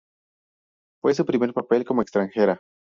ex‧tran‧je‧ra
/eɡstɾanˈxeɾa/